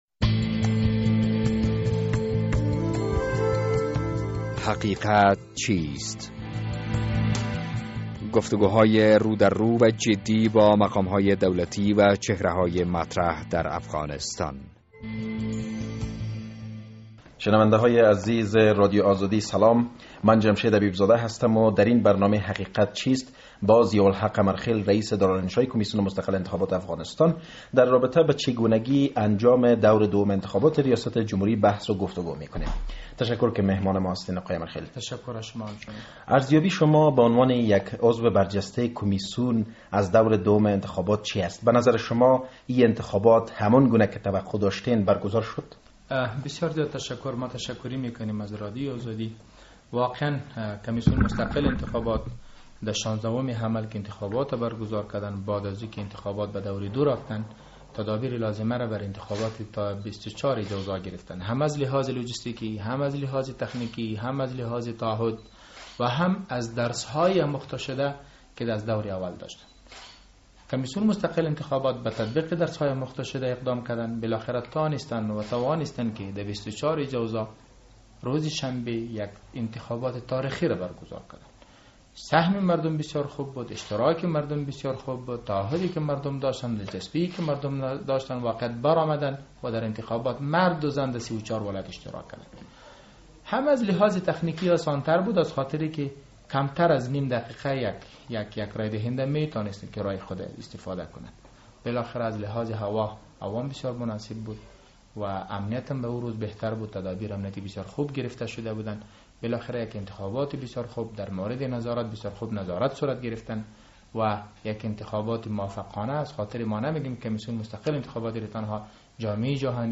دراین برنامه حقیقت چیست با ضیاالحق امرخیل رییس دارالانشاء کمیسیون مستقل انتخابات افغانستان دررابطه به چگونگی انجام دور دوم انتخابات ریاست جمهوری بحث و گفتگو کرده ایم...